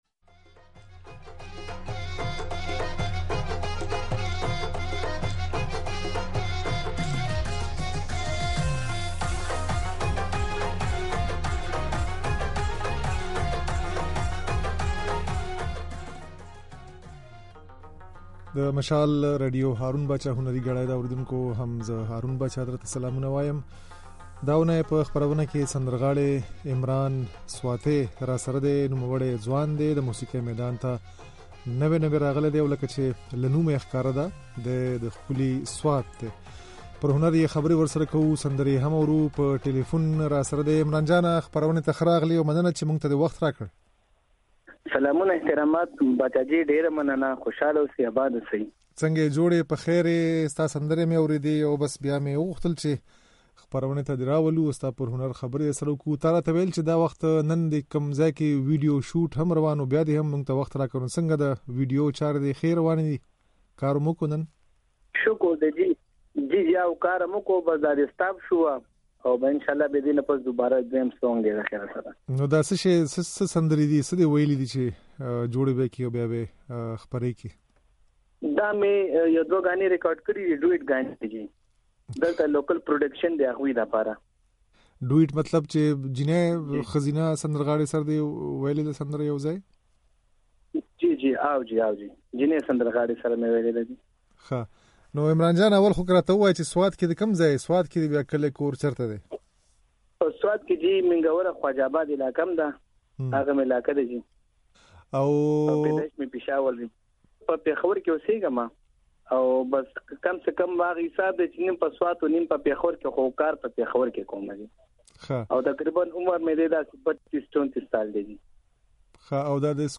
او ځينې سندرې يې د غږ په ځای کې اورېدای شئ